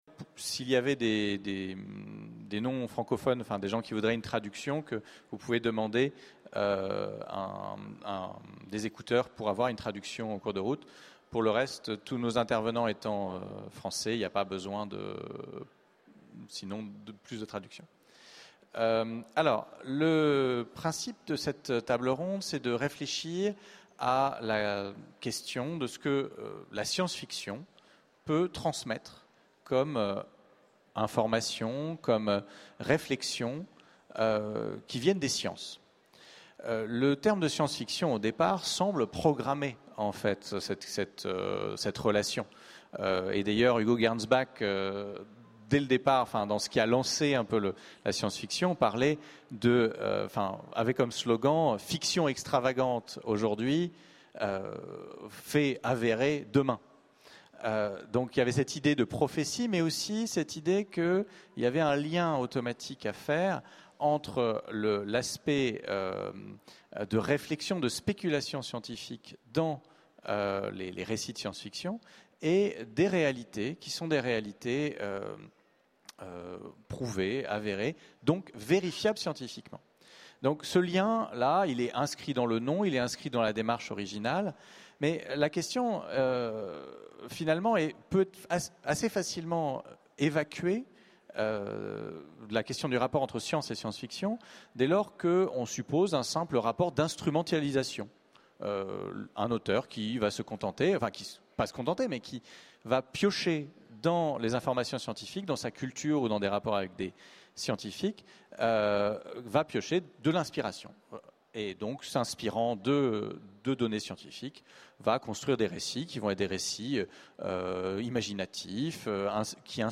Utopiales 12 : Conférence La science-fiction contribue-t-elle à vulgariser les sciences ?